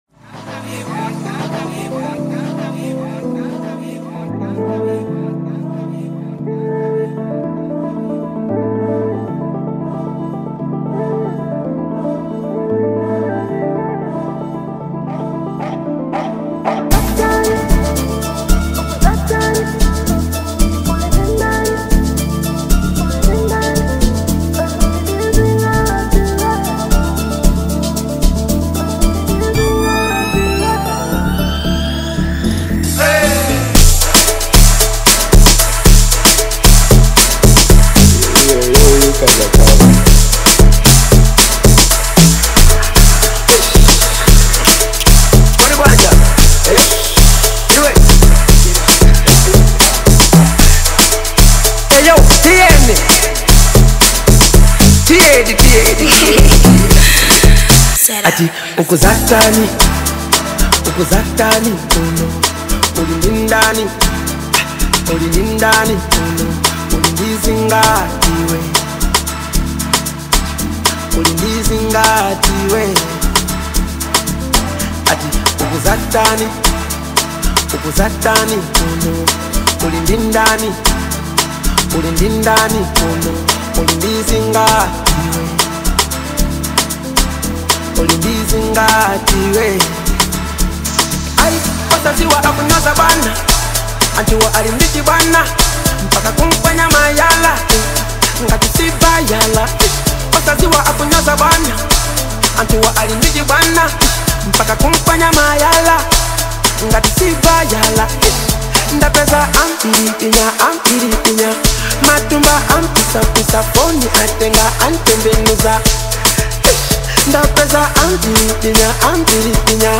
keeping the energy upbeat and catchy